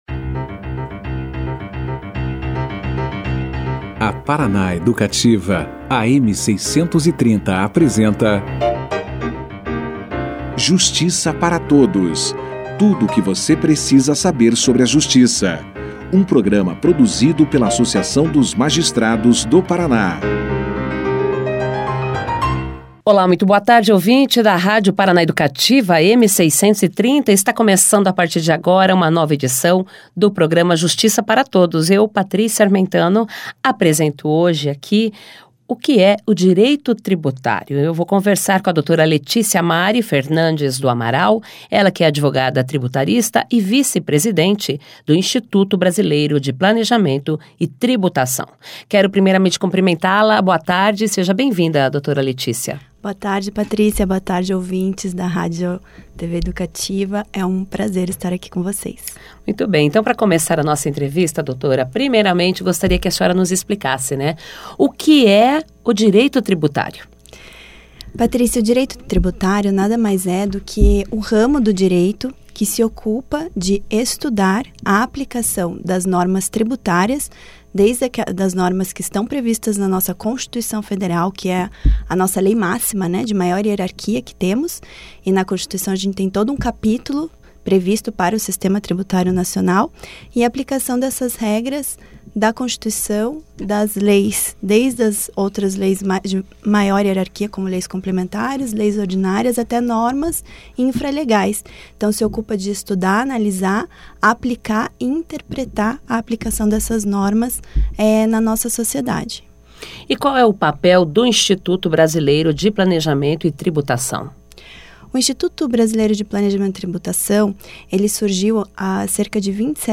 Somente neste ano, até o momento, o impostômetro já chega a marca de 2 trilhões de reais arrecadados. A advogada falou ainda sobre a importância do planejamento tributário na gestão fiscal das empresas. Confira aqui a entrevista na íntegra.